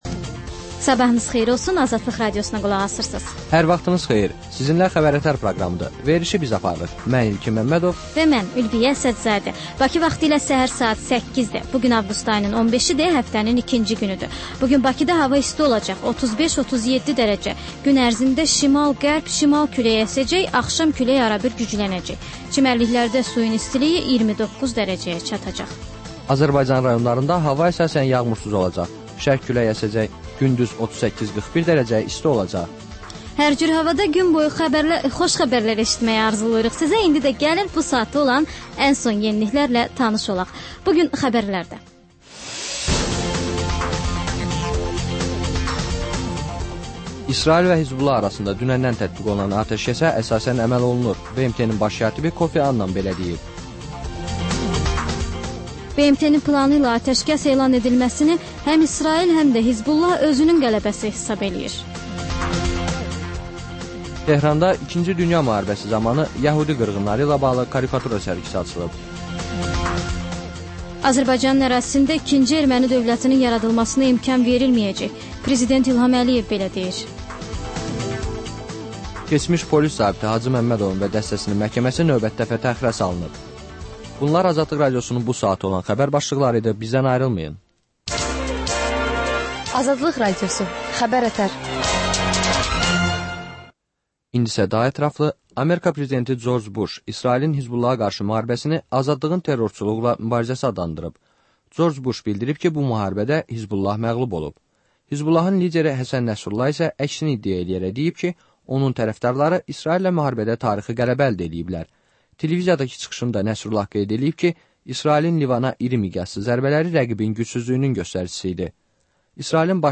Səhər-səhər, Xəbər-ətər: xəbərlər, reportajlar, müsahibələr. Hadisələrin müzakirəsi, təhlillər, xüsusi reportajlar. Və sonda: Azərbaycan Şəkilləri: Rayonlardan reportajlar.